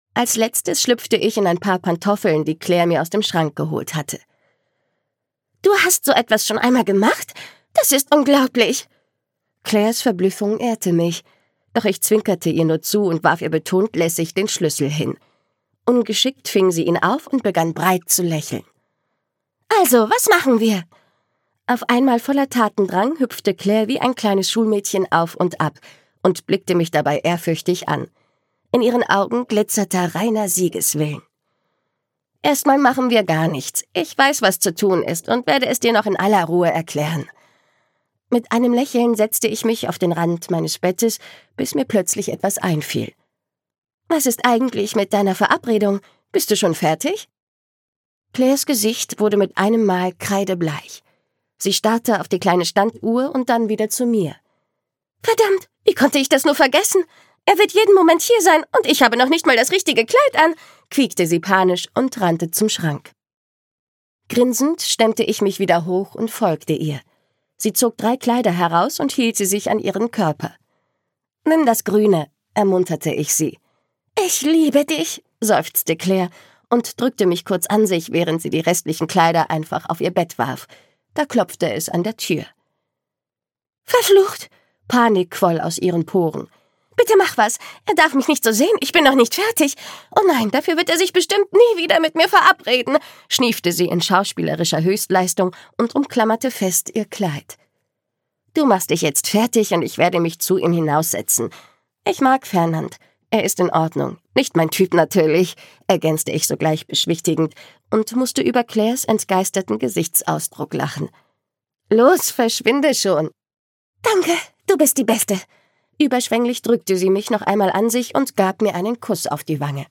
Royal 2: Ein Königreich aus Seide - Valentina Fast - Hörbuch